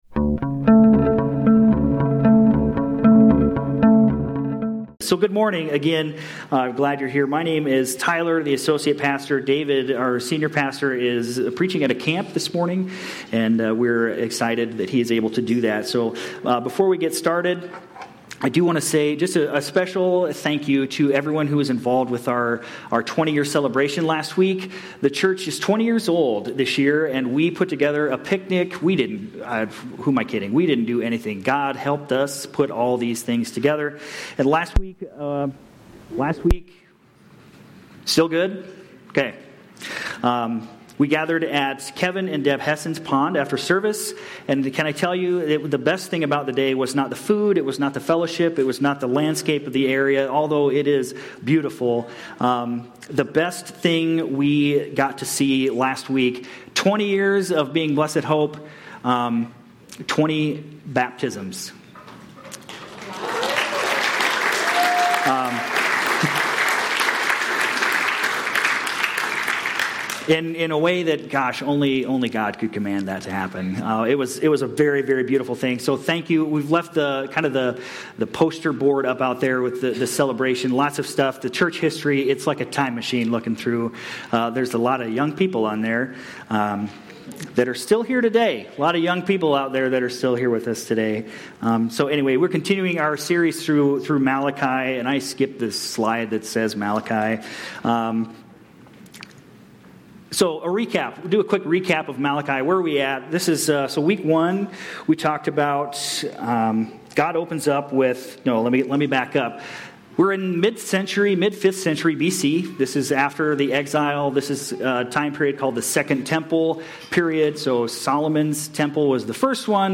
June-29-25-Sermon-Audio.mp3